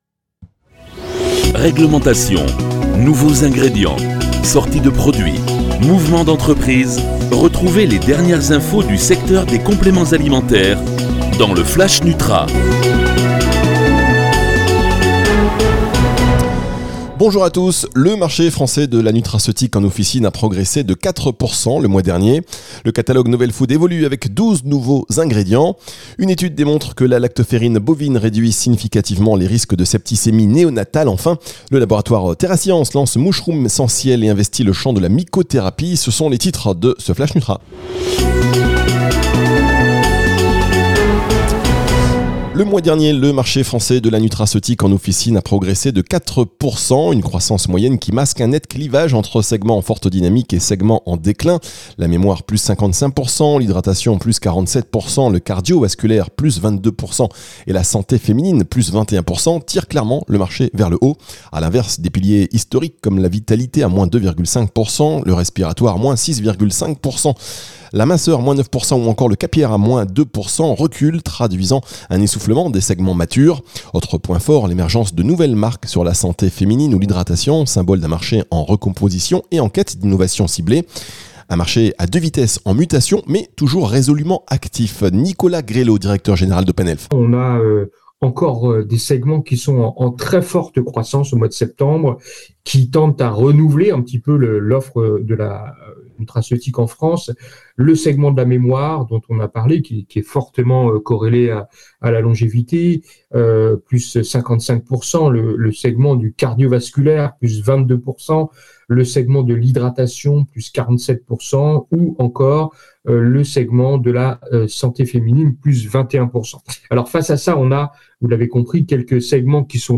Les titres de votre flash Nutra: